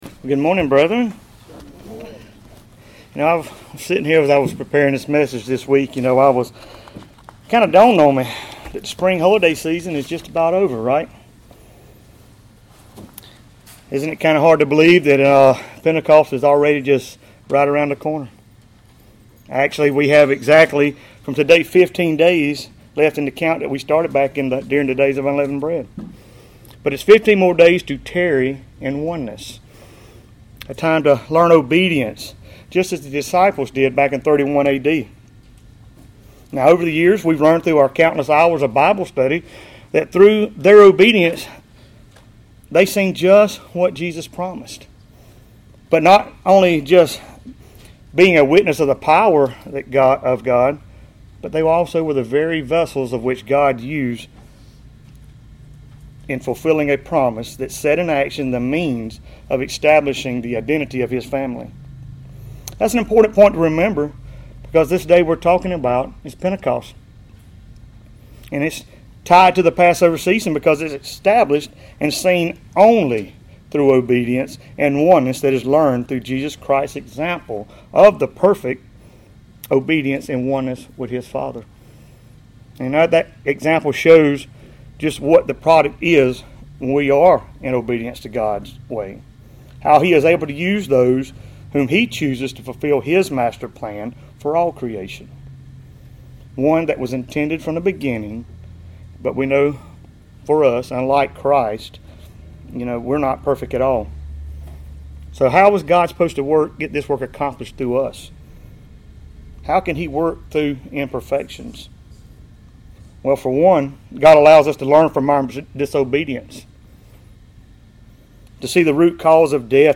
Sermons
Given in Columbus, GA Central Georgia